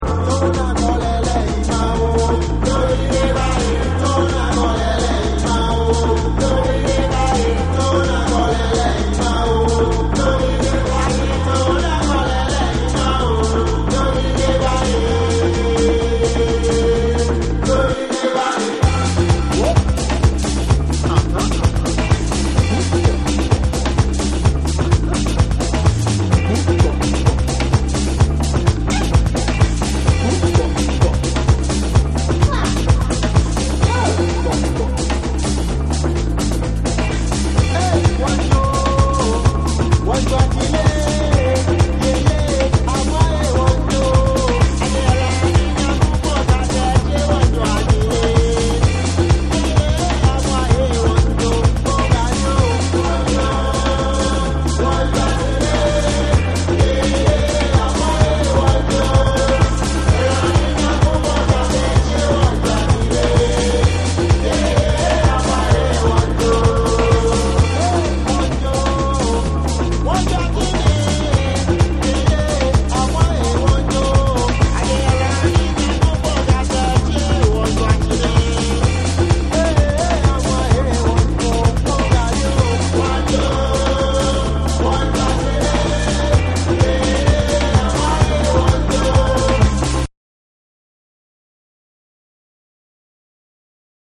ORGANIC GROOVE / TECHNO & HOUSE / TRIBAL